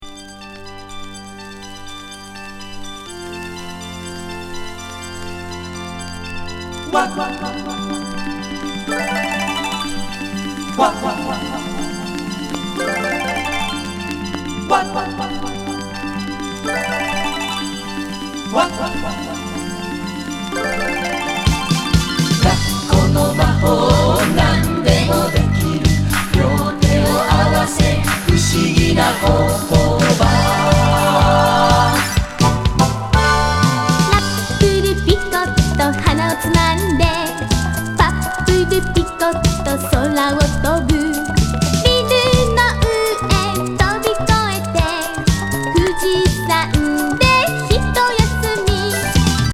トロピカル・ラテン・グルーヴィー・ロック!